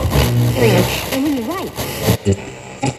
80BPM RAD2-L.wav